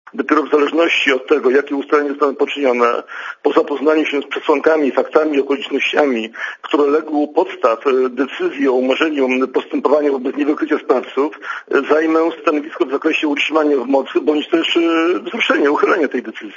Posłuchaj komentarza prokuratora Olejnika